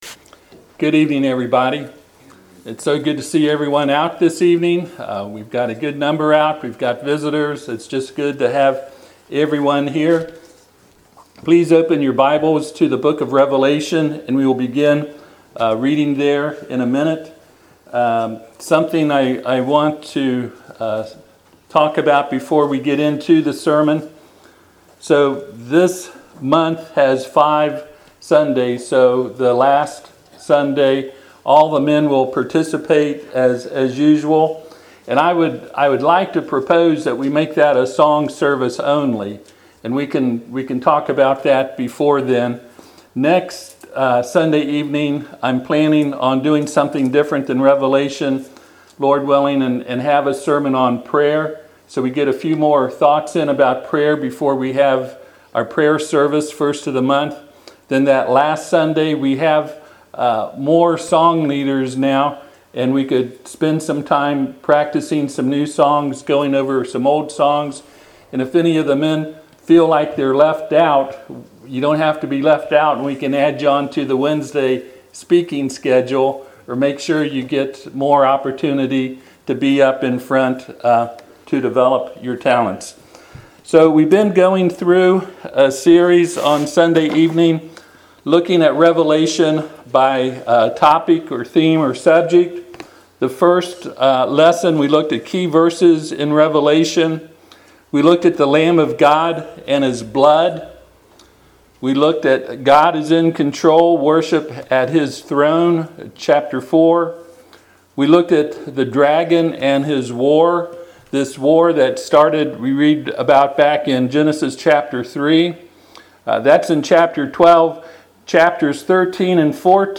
Passage: Revelation 19:11-16 Service Type: Sunday PM